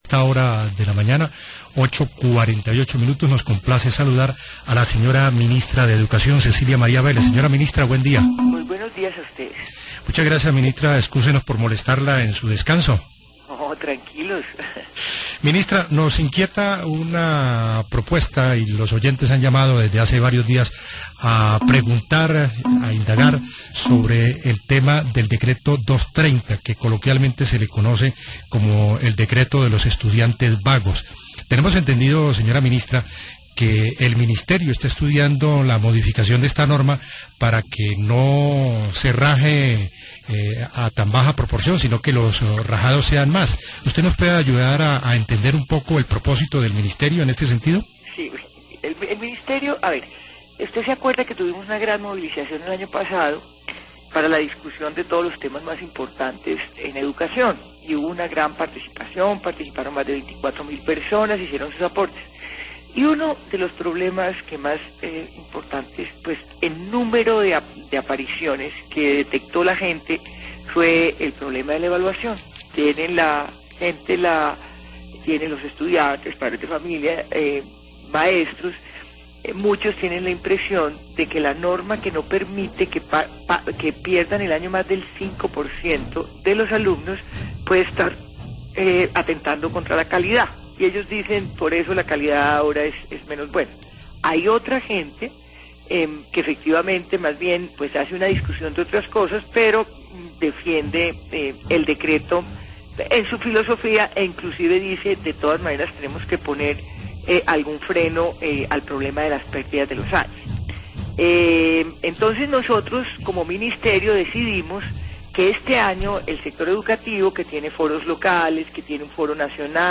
La ministra de educación, Cecilia María Vélez habla sobre la idea de modificar el decreto 230/02.